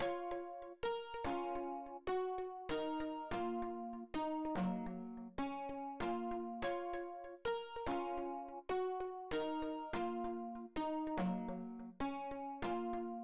Restless_Pluck.wav